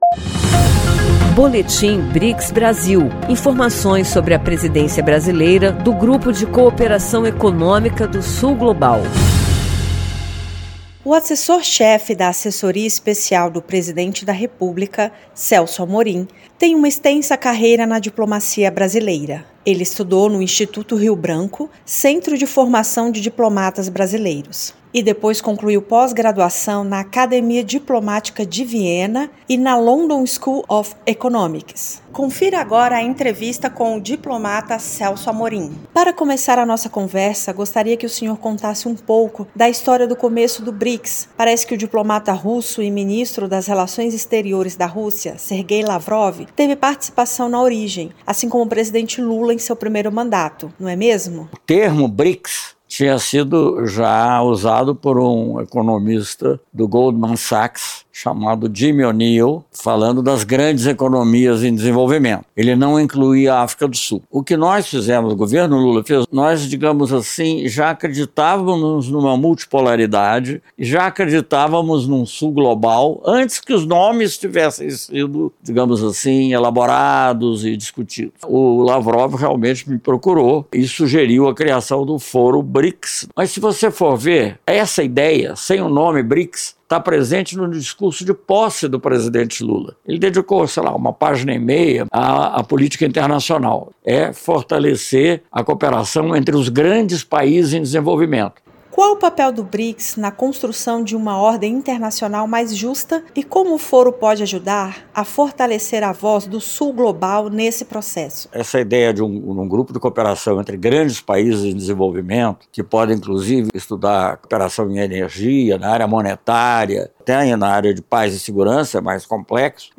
Ele contou um pouco sobre a história da formação do BRICS como um grupo de cooperação. Para ele, a colaboração entre os países do Sul Global é fundamental e ouvir o que eles têm a dizer é inquestionável. Ouça a entrevista e saiba mais.